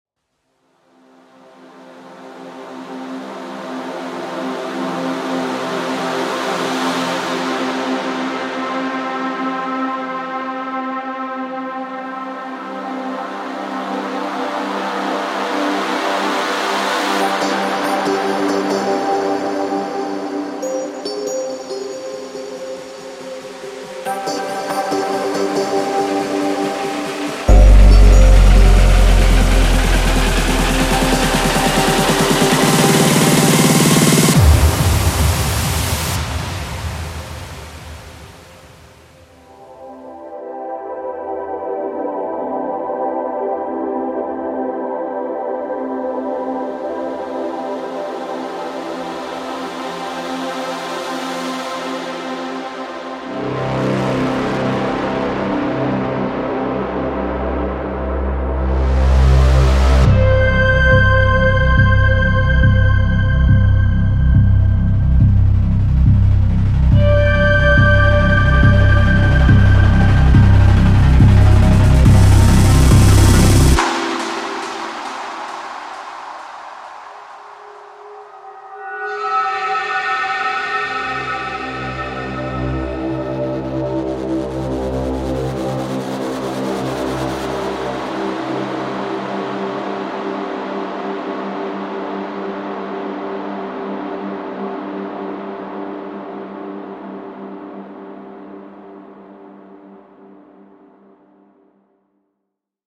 包含220多个电影氛围冲击音效，如上升音、下降音、氛围音、冲击音、低音等效果音效。
上升音和下降音：从轻微的建立到强烈的下降，我们提供了强大的上升音和下降音，为你的曲目营造令人期待的氛围。
延长氛围音：让你沉浸在引人入胜的环境音中，为你的音乐创作提供理想的背景音，创造丰富而沉浸的音频景观。
低音效果：深沉、隆隆的低音将为你的曲目增添分量和冲击力，确保不仅被听到，还能被感受到。
各种效果音：探索各种效果，包括扫过声、飞扬声等，为你的作品增添动感和纹理。
鼓声建立：经过处理的鼓机循环，为house、techno和trance等音乐风格提供了完美的鼓声，让你的作品听起来更加宏大。
声道数：立体声